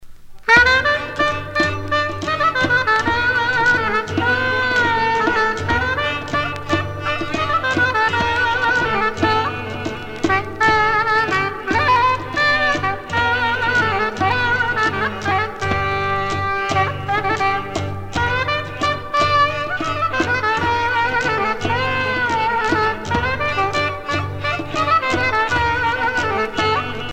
danse : kalamatianos (Grèce)
Pièce musicale éditée